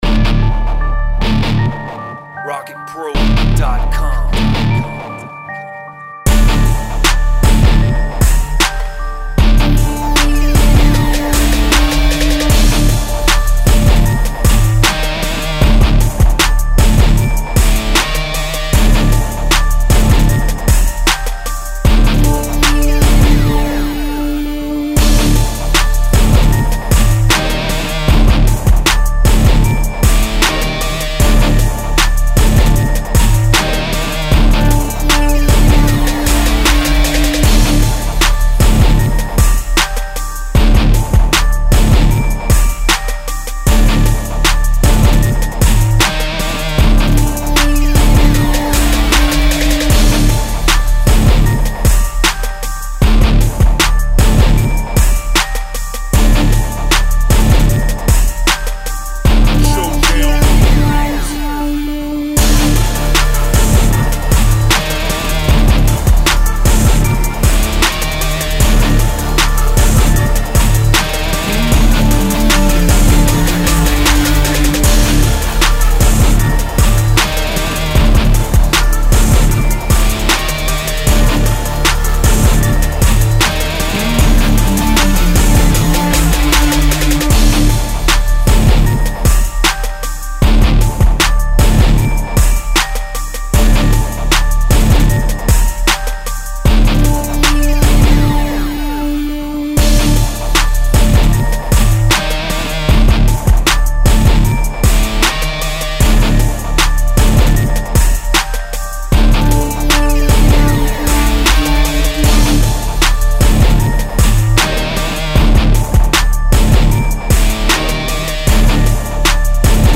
77 BPM. Hardcore rap beat with live electric guitars